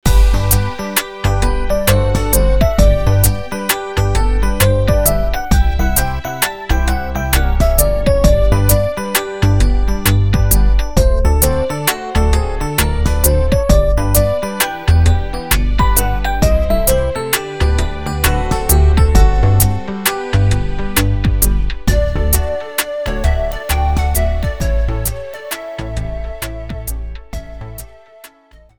Classical music with a modern edge